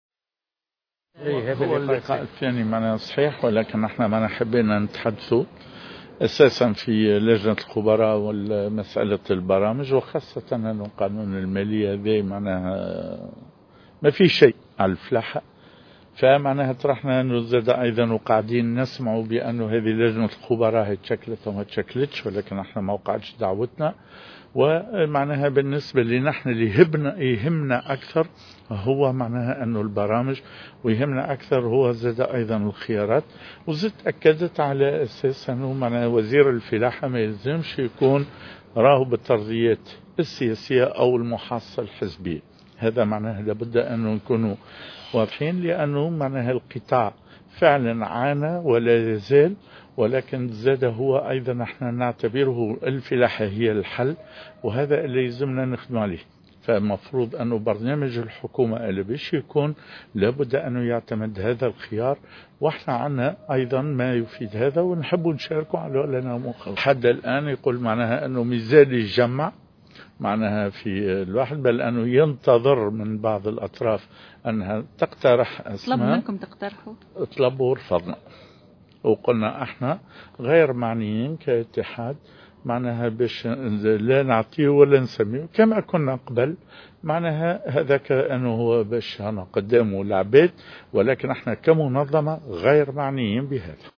وأكد الزار، في تصريح لمراسلة الجوهرة أف أم، أن منظمة الفلاحين، رفضت الاستجابة لطلب الجملي باقتراح شخصية تتولى وزارة الفلاحة، مشددا على تمسك اتحاد الفلاحين بموقفه الرافض للتدخل في مثل هذه التسميات.